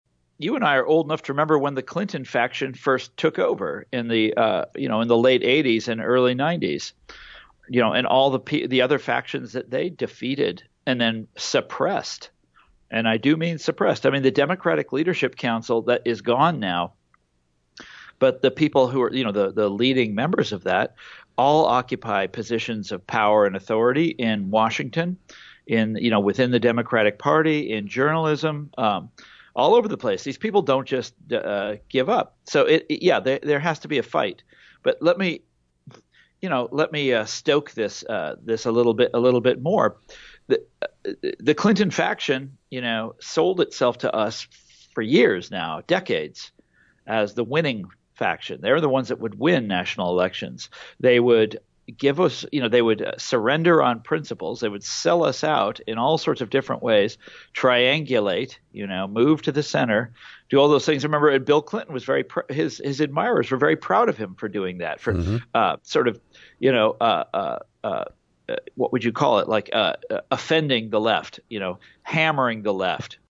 In-Depth Interview: Author and Commentator Thomas Frank Talks About the Divided Democratic Party
It’s another frank, if rambling conversation with Tom Frank.